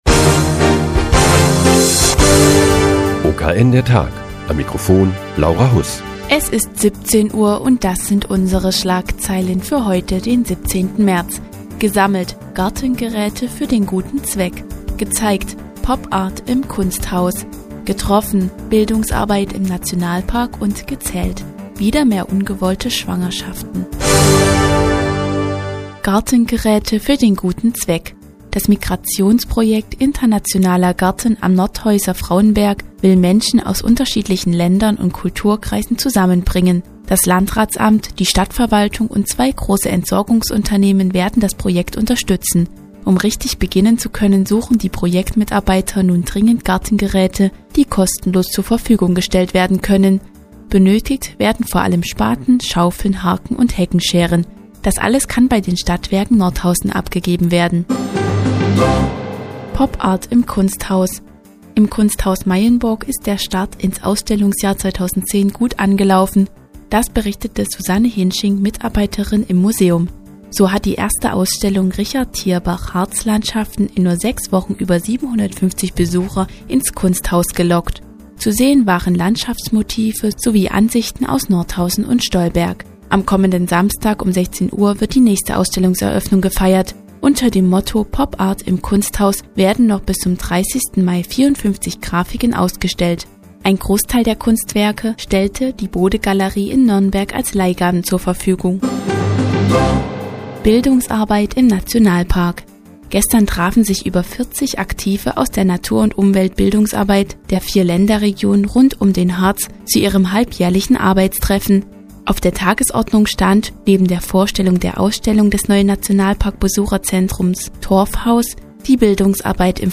Die tägliche Nachrichtensendung des OKN ist nun auch in der nnz zu hören. Heute geht es um das Migrationsprojekt "Internationaler Garten" und die Ausstellung "PopArt im Kunsthaus"